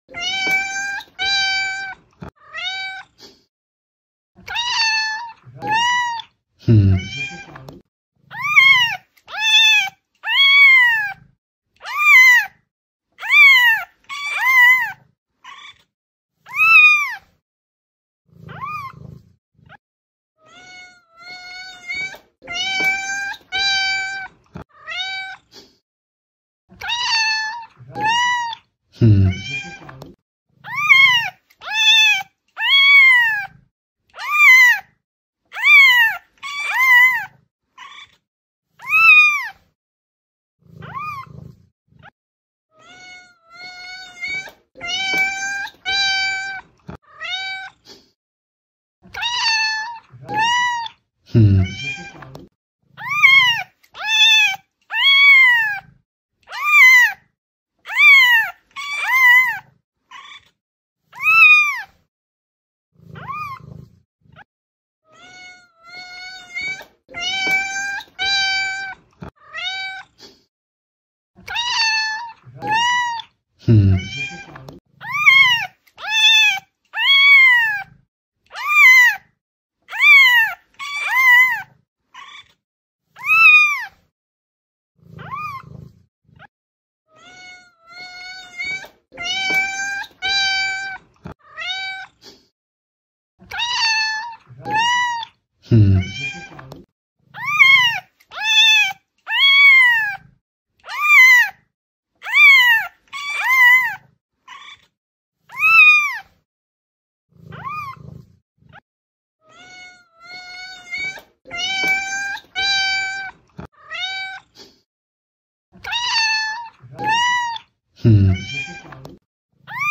Cats Meow Sound Effect Free Download
Cats Meow